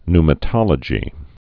(nmə-tŏlə-jē, ny-)